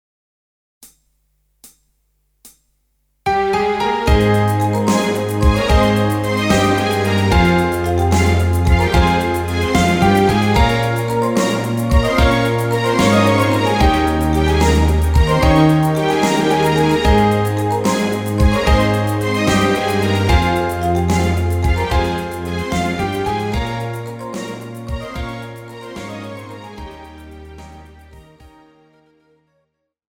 Žánr: Pop
BPM: 73
Key: Ab
MP3 ukázka s ML